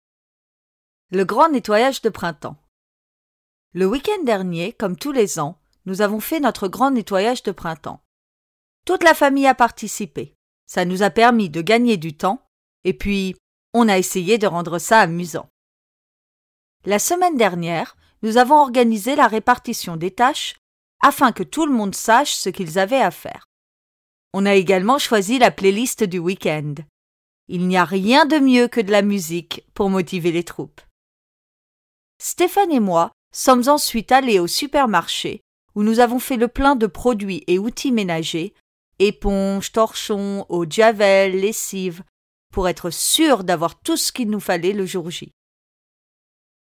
Take part in the big spring cleaning with this audio article.